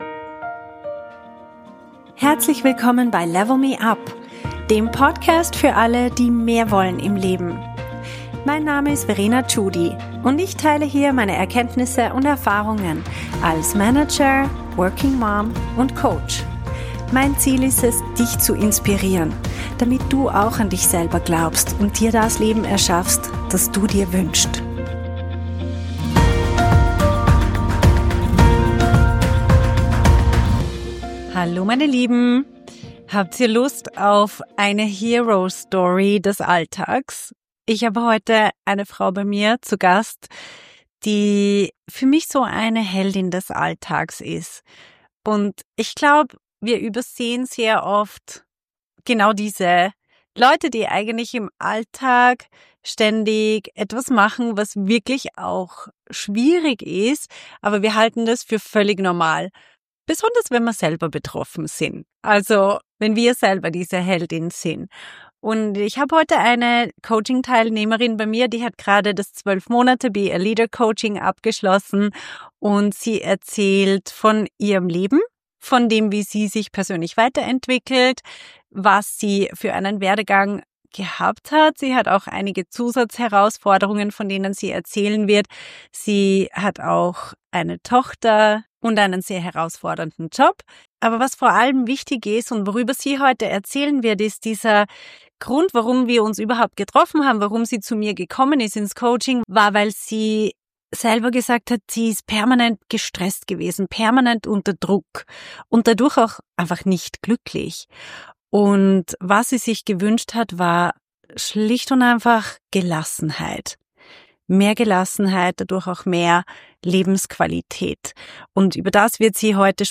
Jung. Ausländerin. Erfolgreich. - Interview